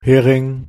Ääntäminen
Synonyymit Hänfling Ääntäminen Tuntematon aksentti: IPA: /ˈheː.rɪŋ/ Haettu sana löytyi näillä lähdekielillä: saksa Käännös Ääninäyte Substantiivit 1. hareng {m} France 2. sardine {f} France Artikkeli: der .